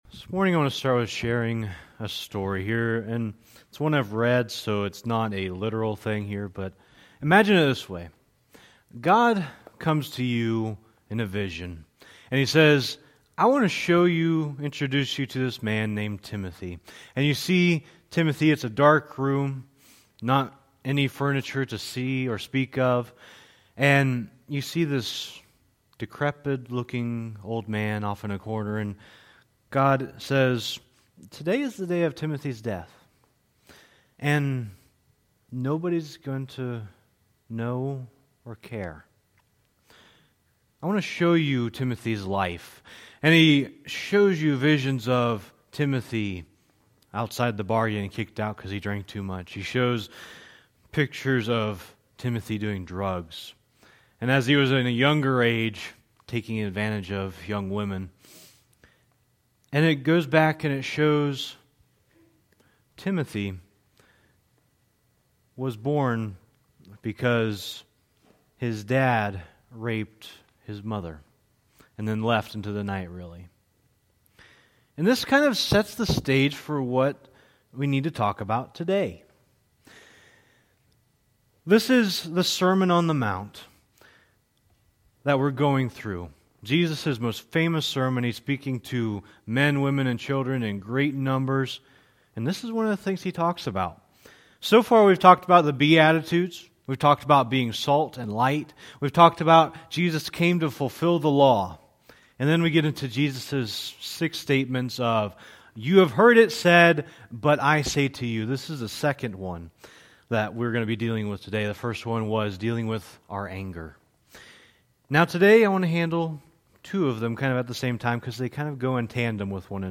Series: Sermon on the Mount Tagged with divorce , Lust , sexual immorality